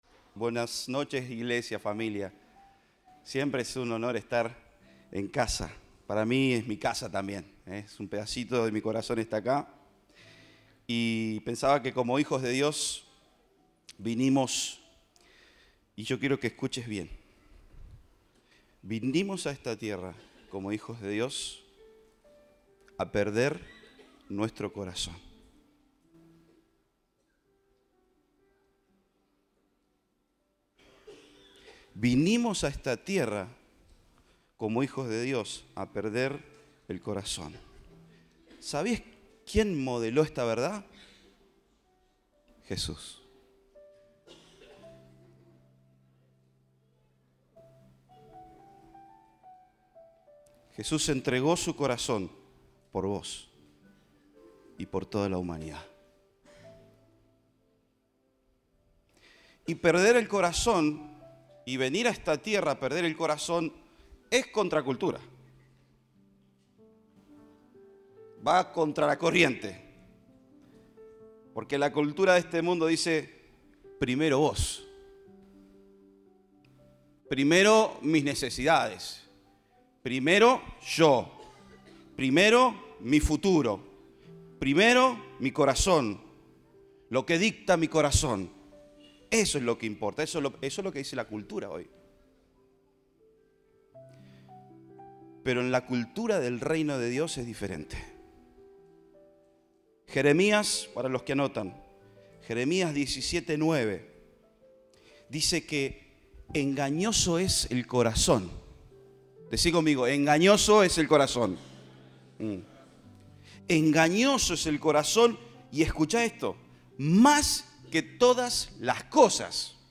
Compartimos el mensaje del Domingo 14 de Mayo de 2023 Orador invitado